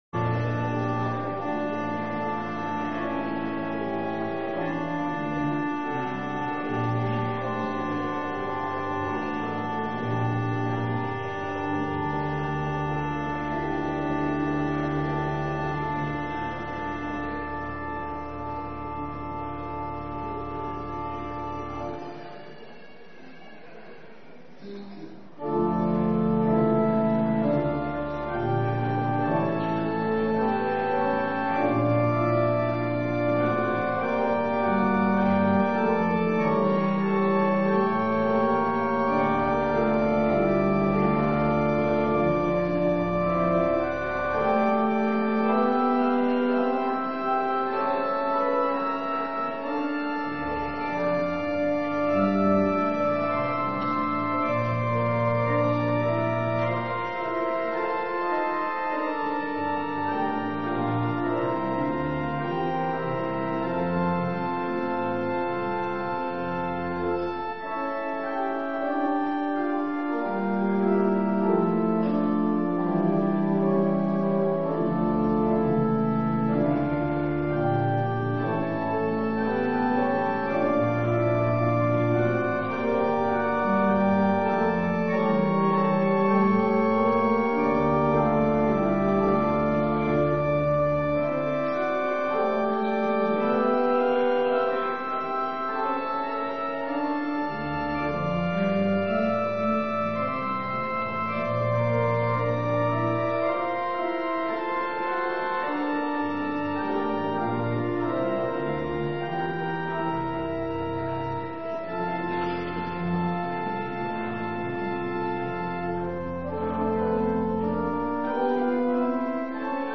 Terugluisteren preken